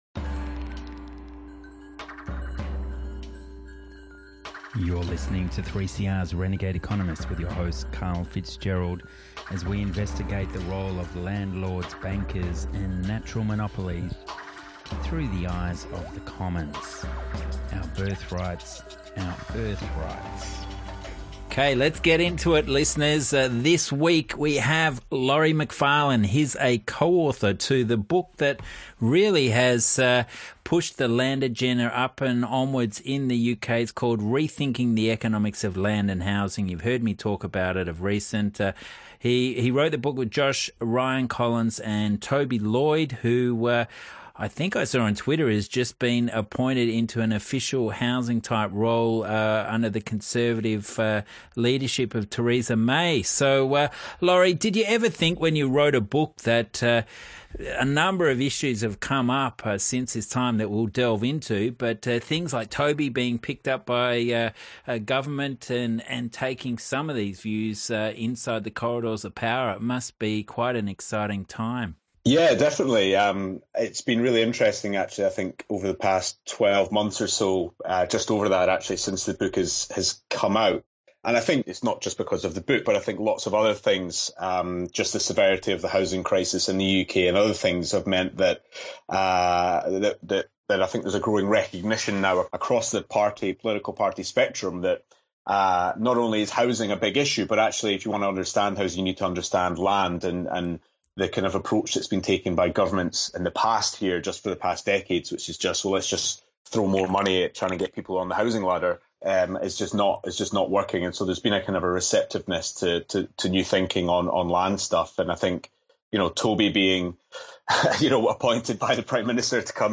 Interview, Rethinking the Economics of Land and Housing